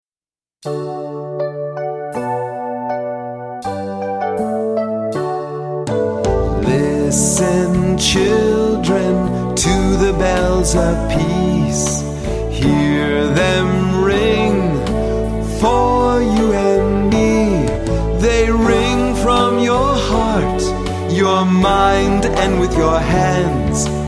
Repeat chorus and echo sounds of bells